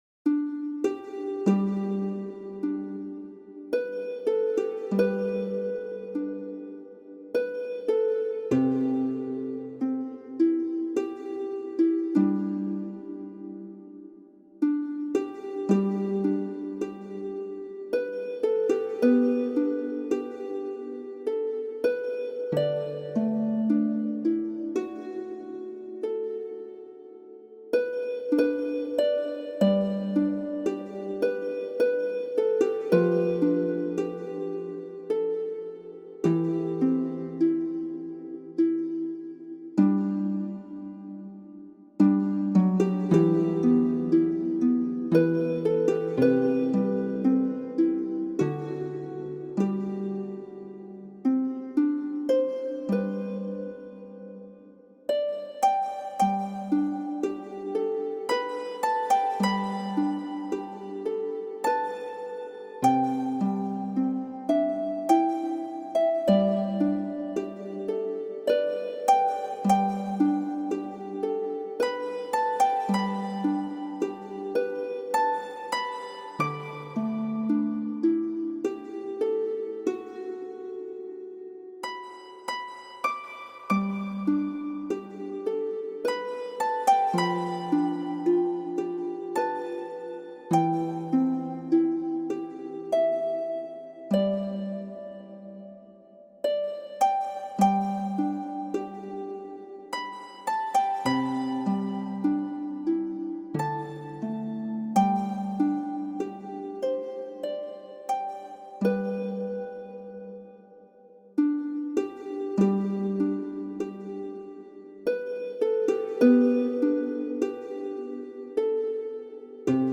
Amazing Grace : harpe apaisante pour détente et repos